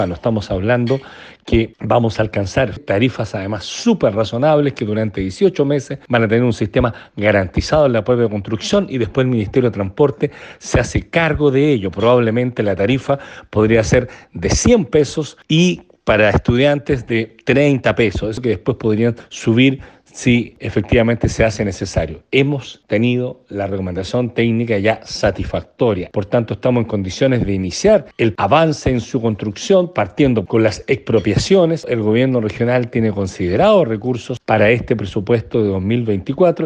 Asimismo, el gobernador de Los Lagos, Patricio Vallespín, dijo que proyectan el precio del pasaje en 100 pesos, aproximadamente.